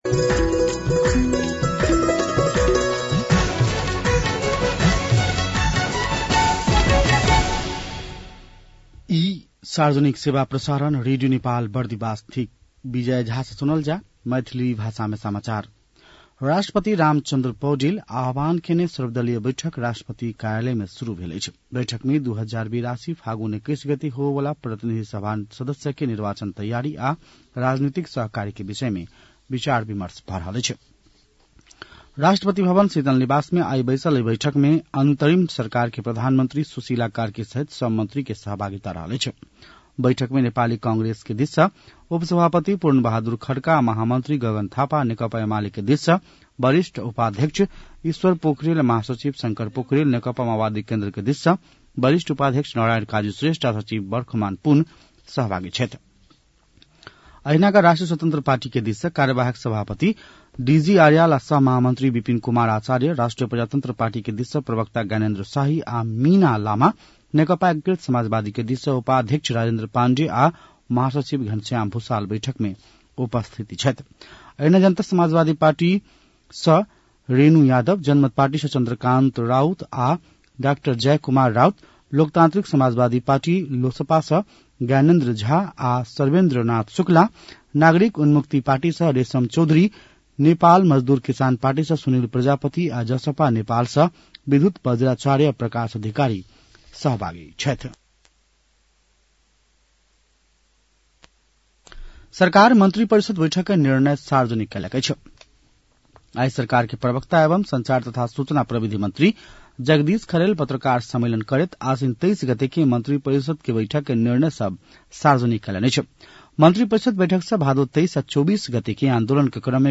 An online outlet of Nepal's national radio broadcaster
मैथिली भाषामा समाचार : २४ असोज , २०८२